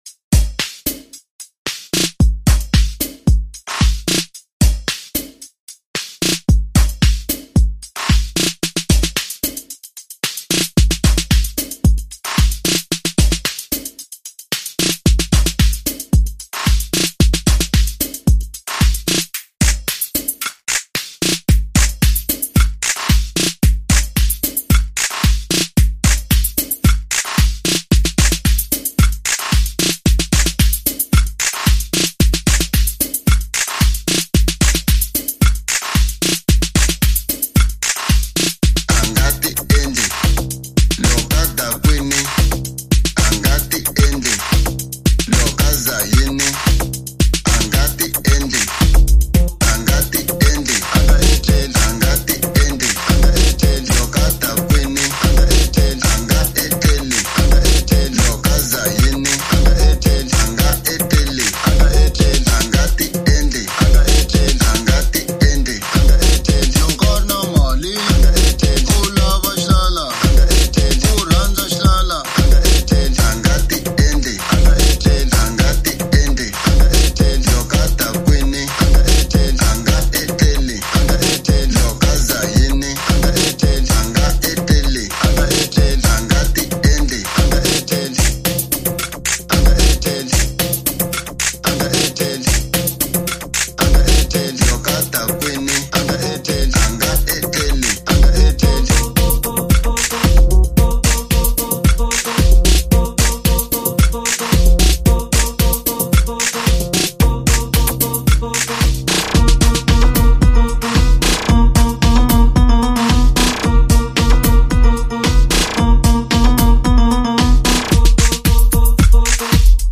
Local House